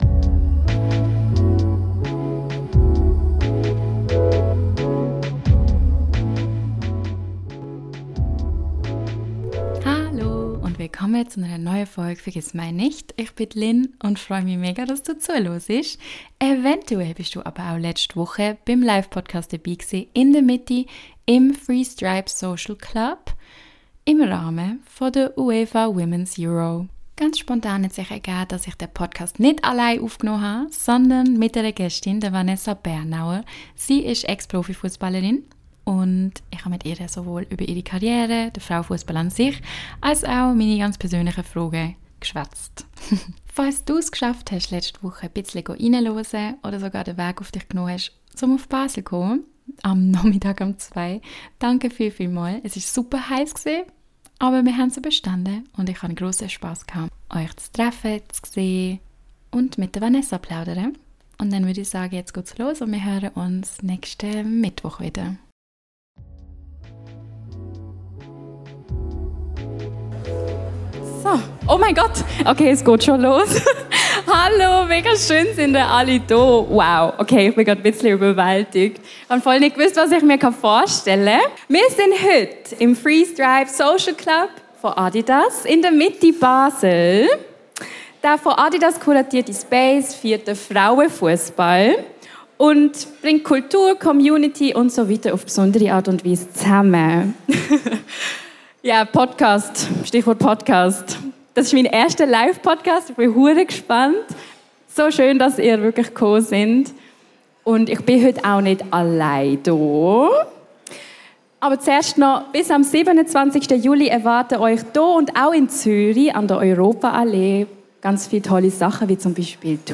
Beschreibung vor 9 Monaten Im Rahme vo de WEURO 2025 hani im Three Stripe Social Club vo Adidas dörfe en Livepodcast halte.
Das isch min erste Livepodcast gsi - Danke fürs debi si!